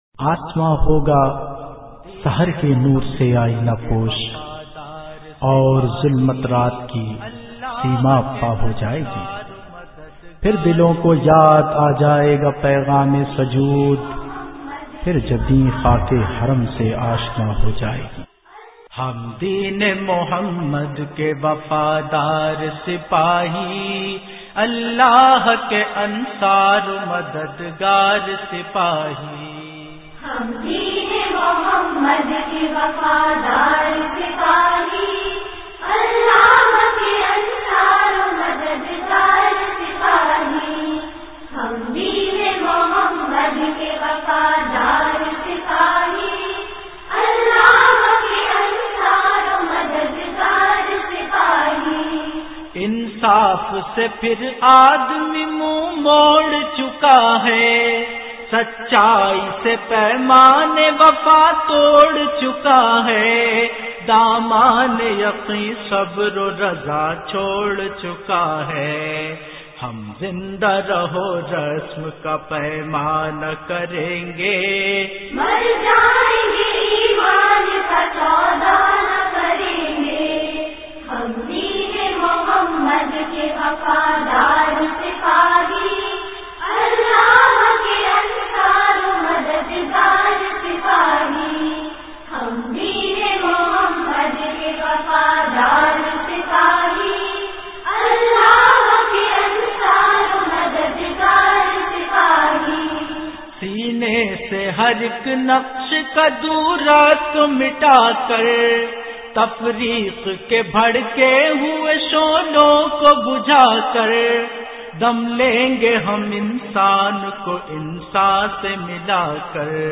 Islamic Qawwalies And Naats
Nazmain